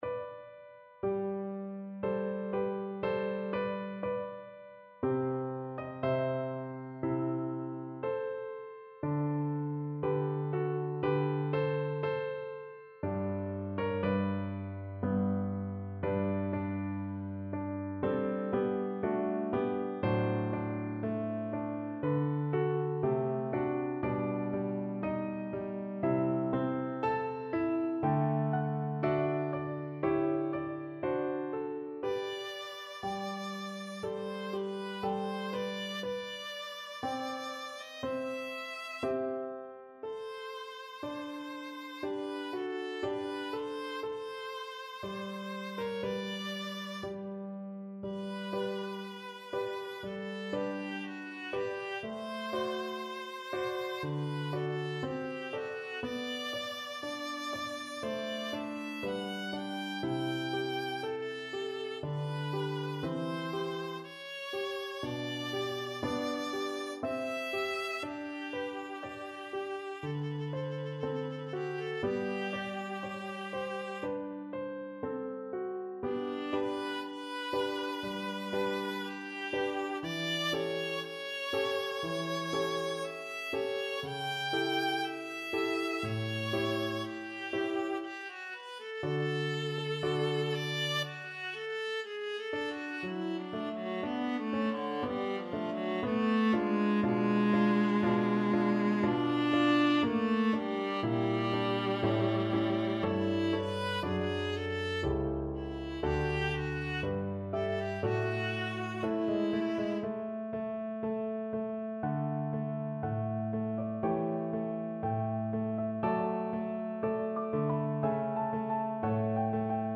4/4 (View more 4/4 Music)
Adagio = c. 60
Viola  (View more Intermediate Viola Music)
Classical (View more Classical Viola Music)